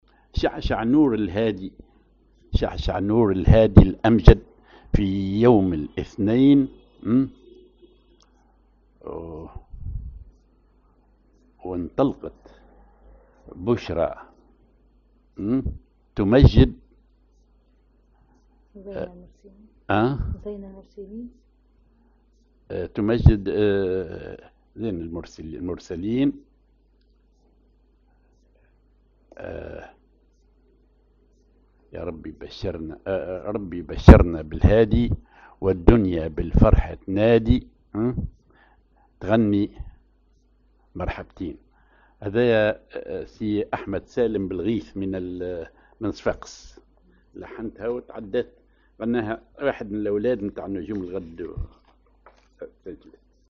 Maqam ar محير عراق على درجة الجهاركاه
Rhythm ar دخول البراول
genre أغنية